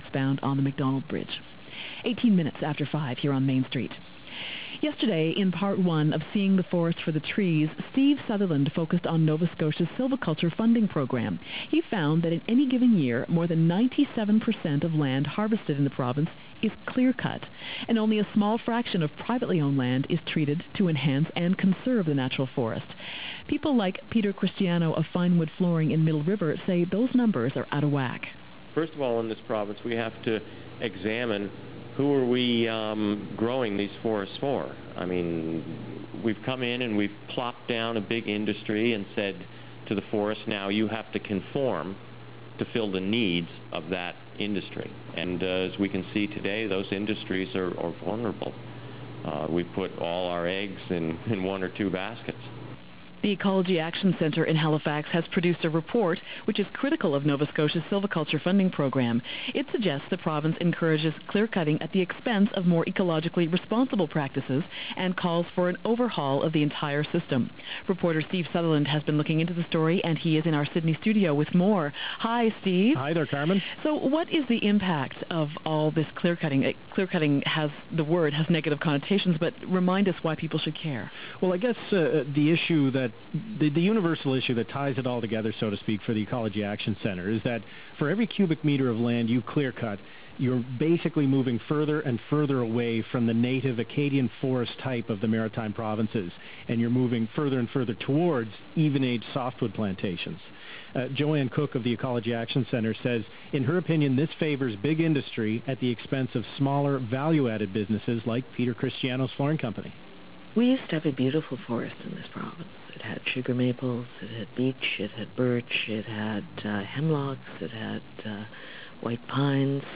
We produced an in-depth report on the Forest Sustainability Regulations and their effects on uneven-aged forestry practices. The subject received considerable media attention, including a 2-part CBC radio documentary that was aired several times (listen to Part 1 here and Part 2 here ).